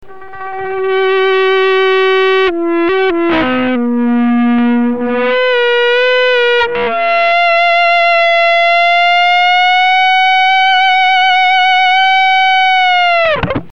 Все семплы записывались через преамп MesaBoogie V-Twin в линию (каналы clean и solo), дополнительно не обрабатывались.
Пример 6 - сустейнер в стандартном режиме на перегрузе, пример звукоизвлечения без щипка.
sust_wout_attack.mp3